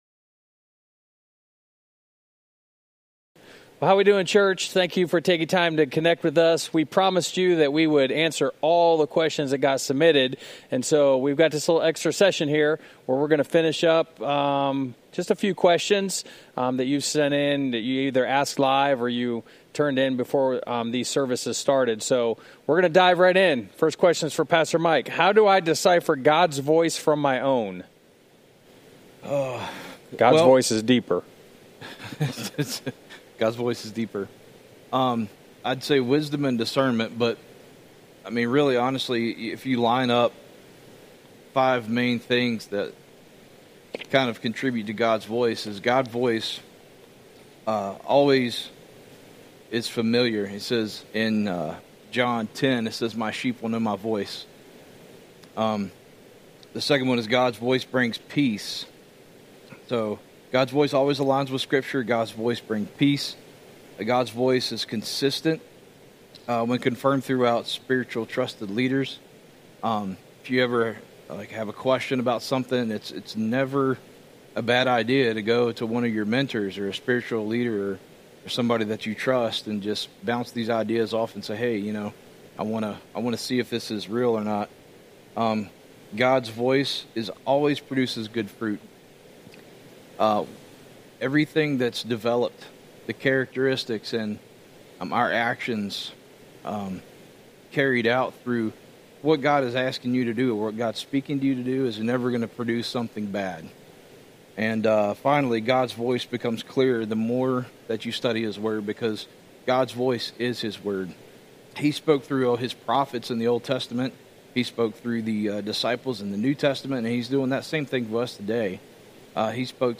This is our Extra Q&A session with our pastors.